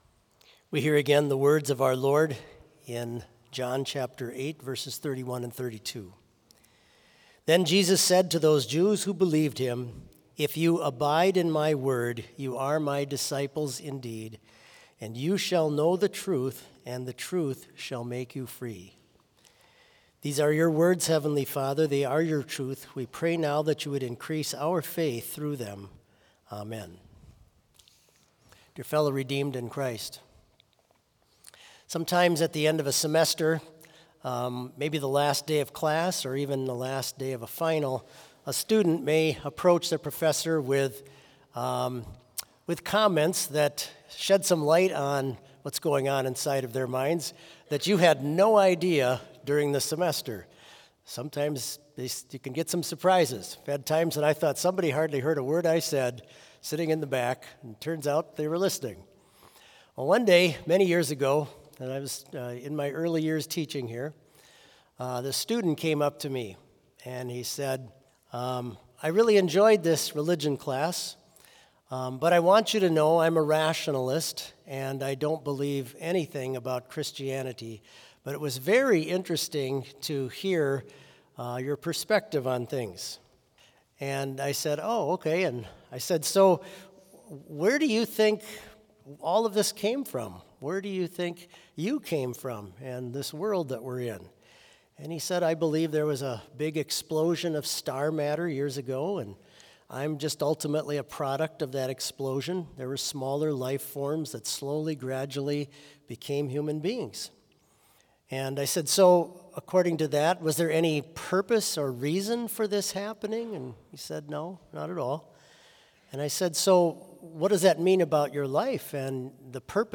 Complete service audio for Commencement Vespers - Thursday, May 8, 2025